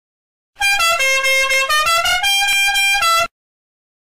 Goofy Ahh Car Horn sound effects free download